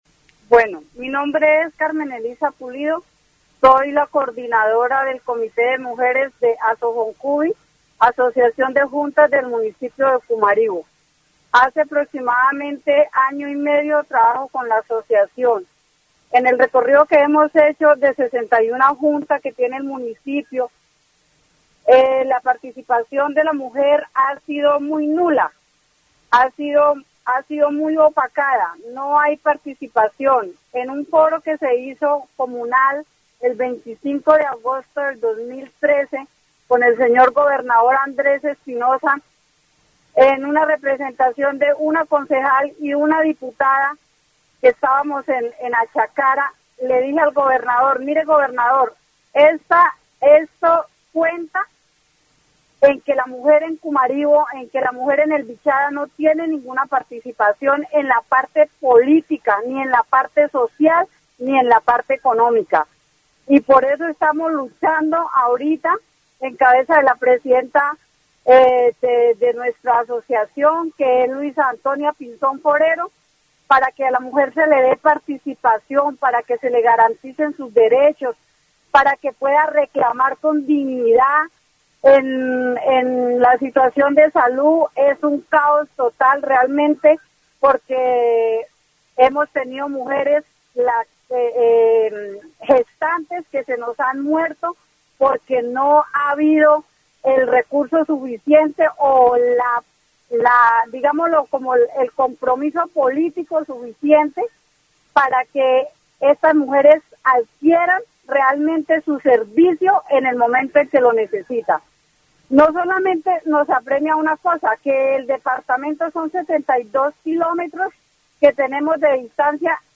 Emisora LAUD 90.4 FM
dc.subject.lembProgramas de radio